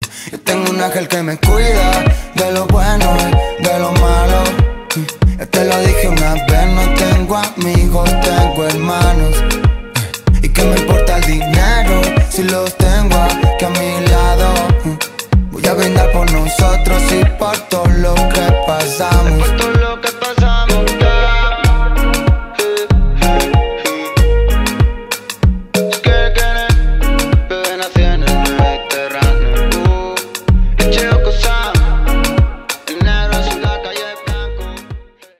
música Pop